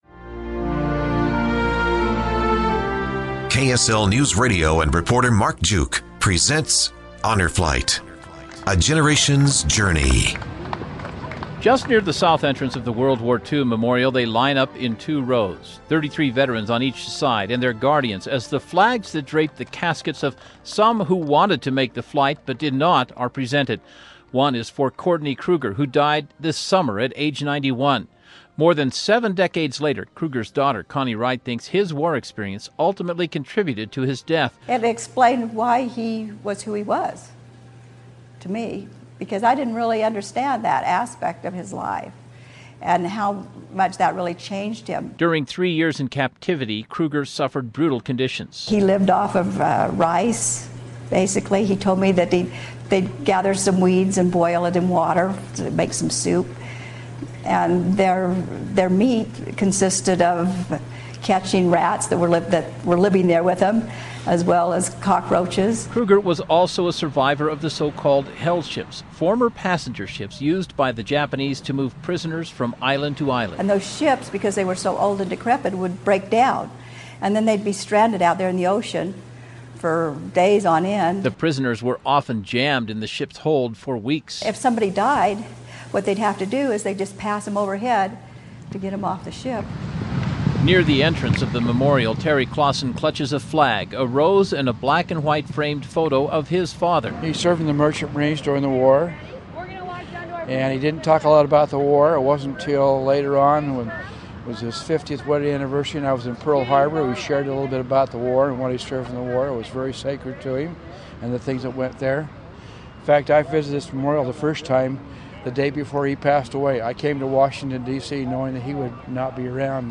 Part 2: Utah Honor Flight / KSL Newsradio documentary